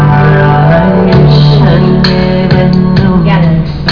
lovely ballads which simply serenade every soul